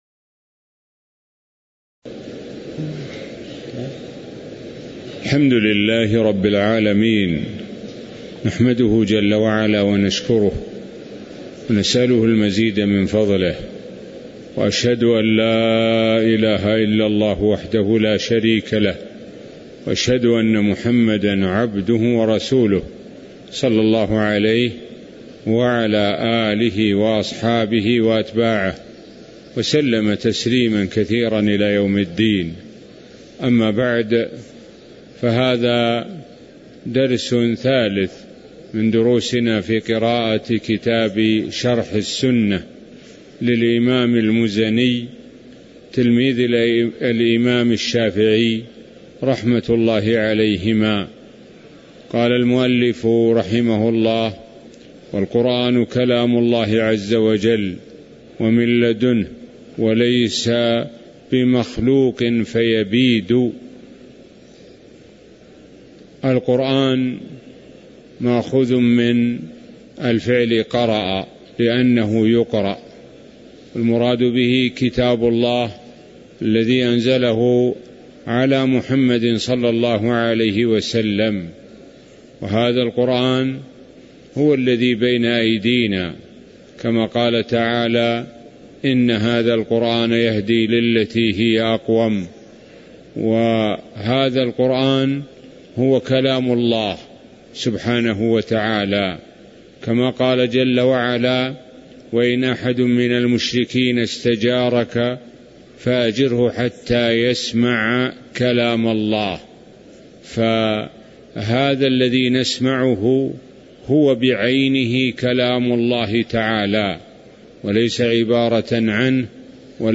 تاريخ النشر ١٨ ذو القعدة ١٤٤٣ هـ المكان: المسجد النبوي الشيخ: معالي الشيخ د. سعد بن ناصر الشثري معالي الشيخ د. سعد بن ناصر الشثري قوله: والقرآن كلام الله عز وجل ومن لدنه (04)